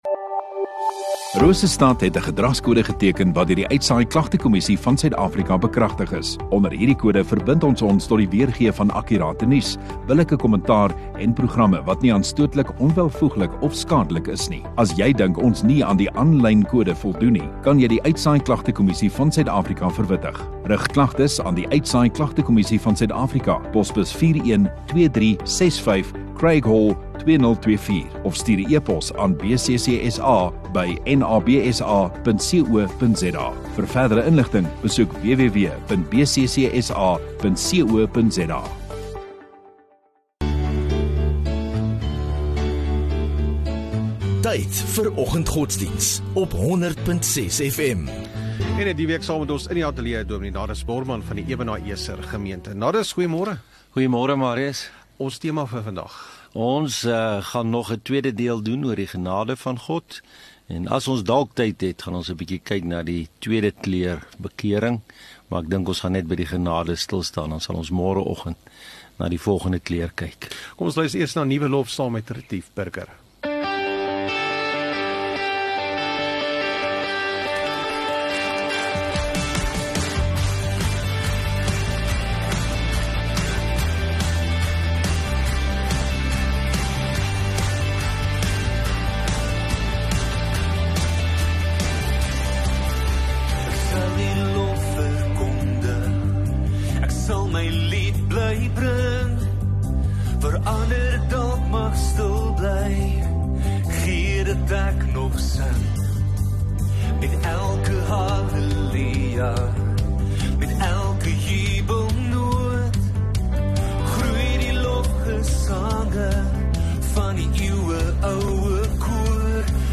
16 Oct Woensdag Oggenddiens